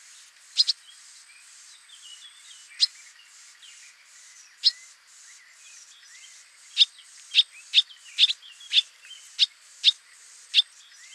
Hirundo rustica - Swallow - Rondine
DATE/TIME: 21/july/2007 (10 a.m.) - IDENTIFICATION AND BEHAVIOUR: three birds are perched on a dead tree on the banks of a pond near the Ombrone river. They call several times. - POSITION: Voltina pond near Grosseto, LAT.N 42°43'/ LONG.E 11°05' - ALTITUDE: +0 m. - VOCALIZATION TYPE: contact calls. - SEX/AGE: unknown - COMMENT: Blackcap and Blackbird song in background. Time interval between calls has been reduced in the spectrograms and in the audio sample. True average interval: 1.8 s (D.S.: 0.9; range: 0.2/3.2; n=20). - MIC: (P)